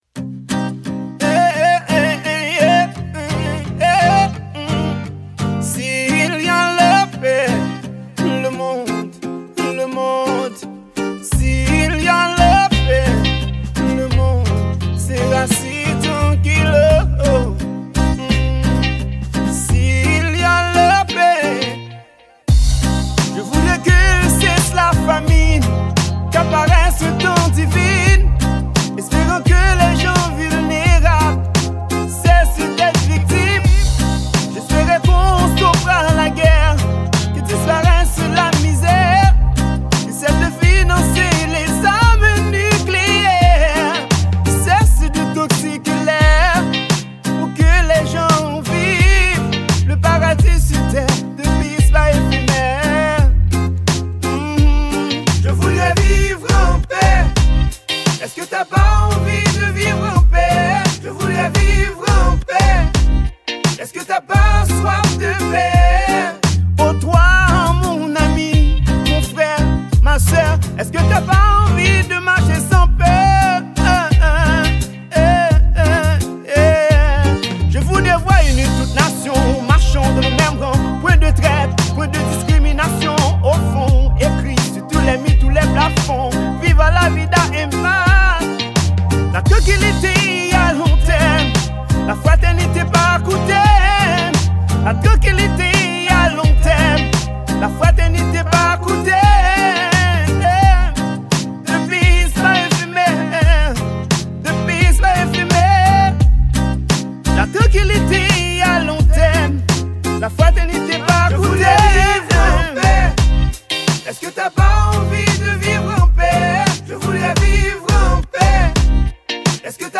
Genre: SLAM.